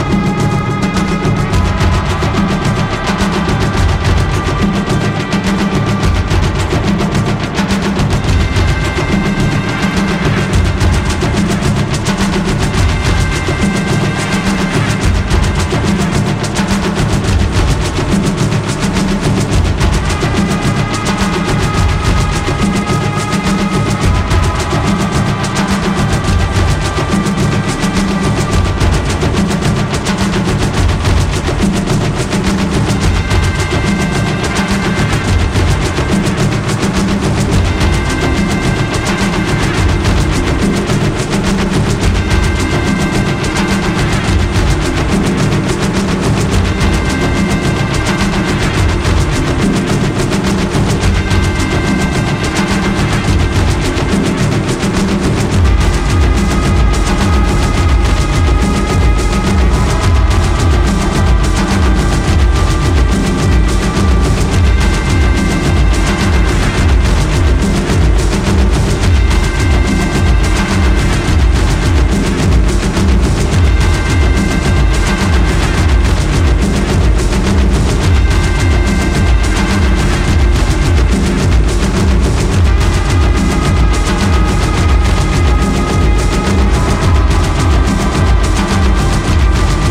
IDM/Electronica